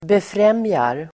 Uttal: [befr'em:jar]